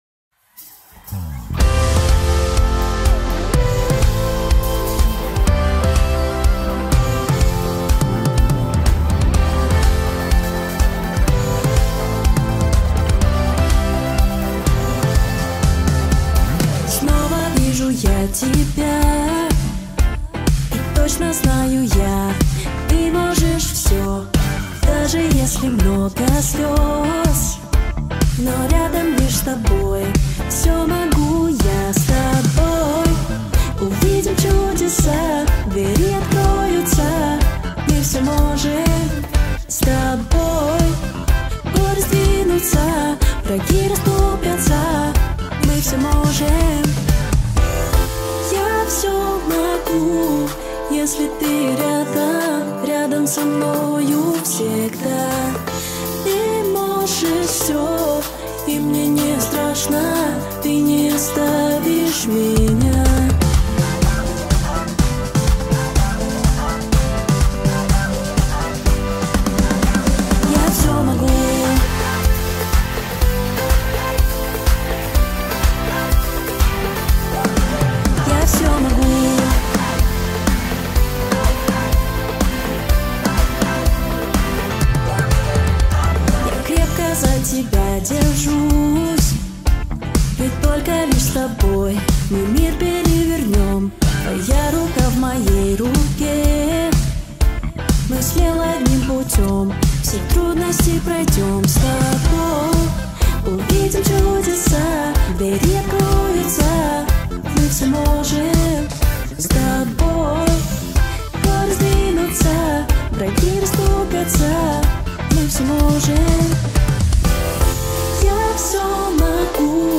песня
297 просмотров 43 прослушивания 5 скачиваний BPM: 124